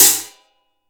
Index of /90_sSampleCDs/AKAI S6000 CD-ROM - Volume 3/Hi-Hat/14INCH_FLANGE_HI_HAT